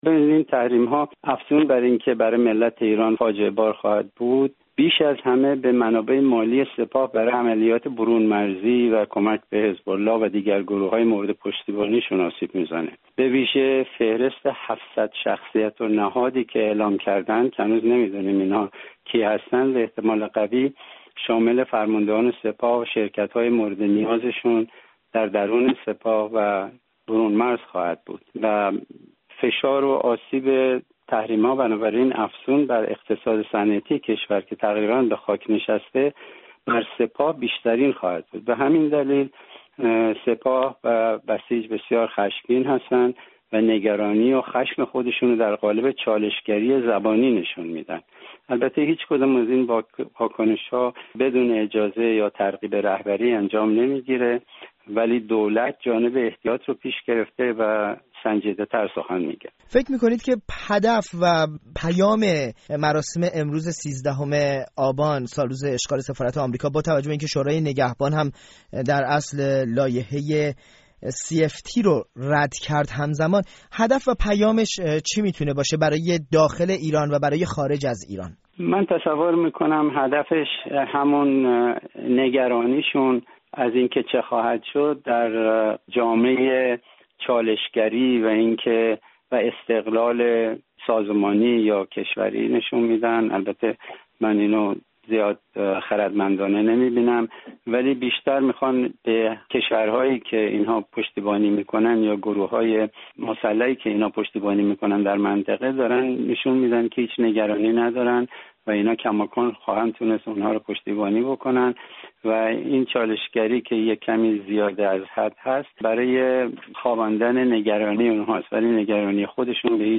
گفت‌وگوی